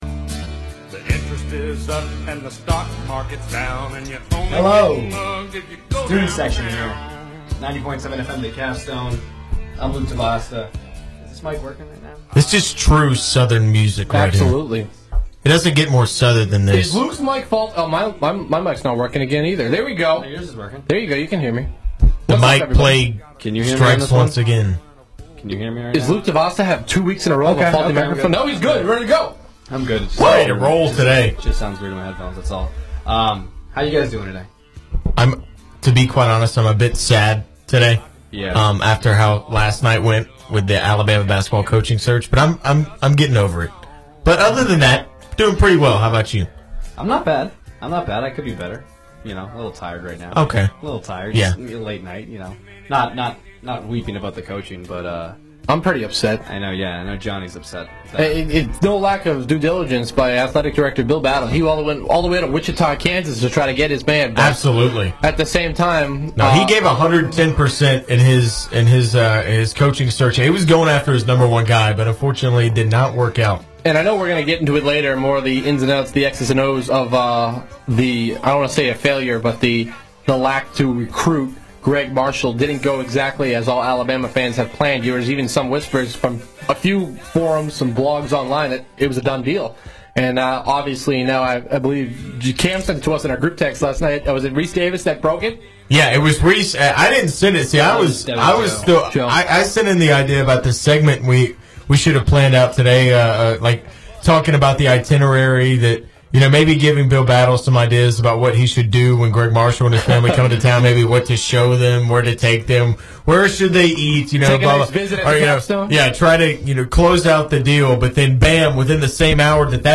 WVUA-FM's and Tuscaloosa's longest running sports talk show "The Student Section"